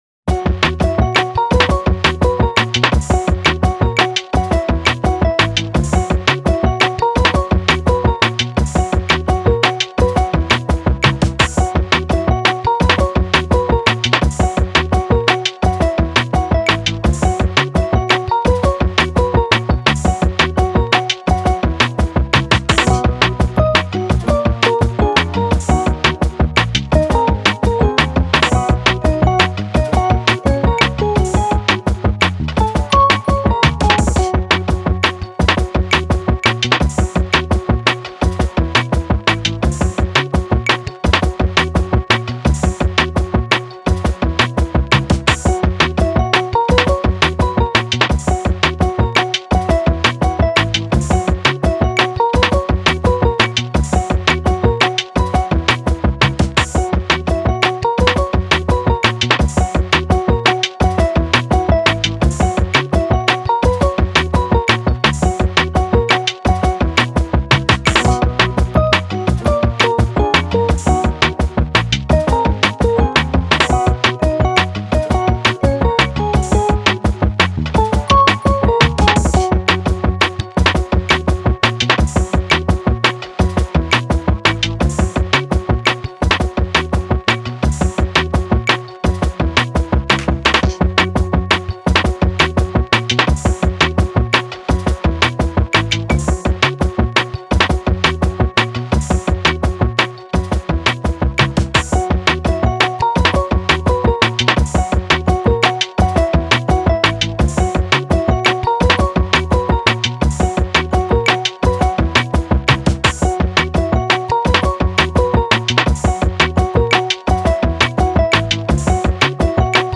フリーBGM
明るい・ポップ